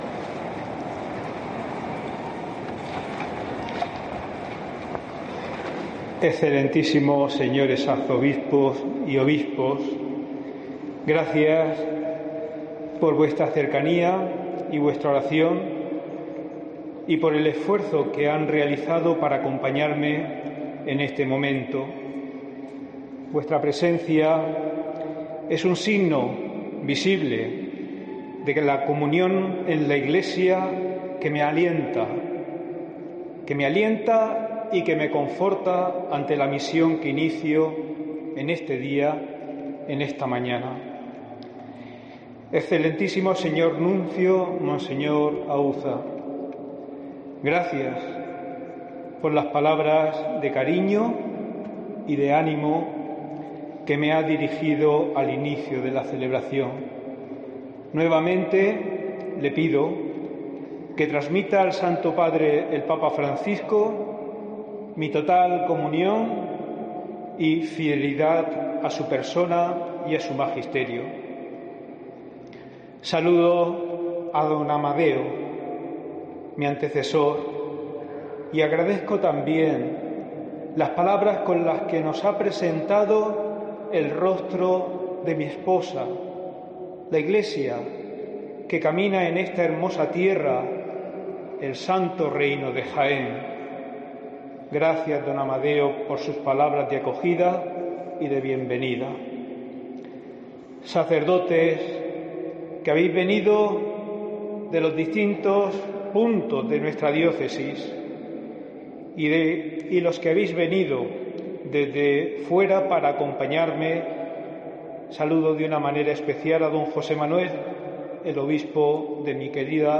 Primera homilia del Obispo de Jaén, Don Sebastián Chico